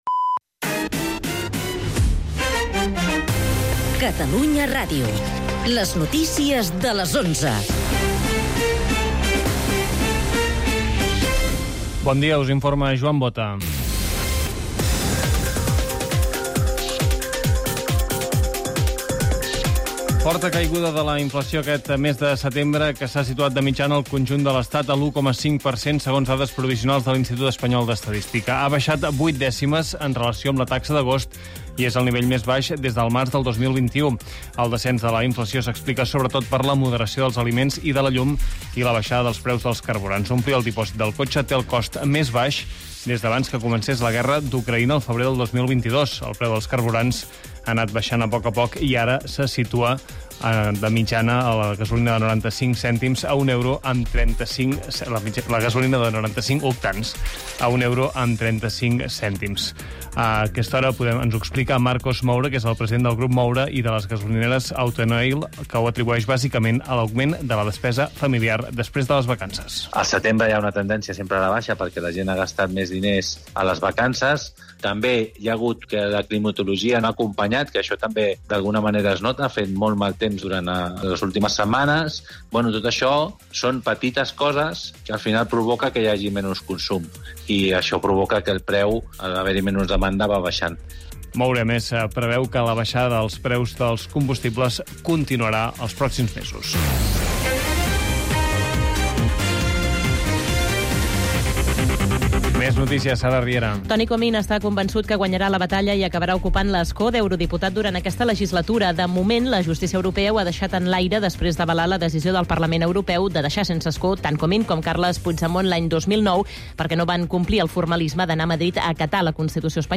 El mat, d'11 a 12 h (entrevista i humor) - 27/09/2024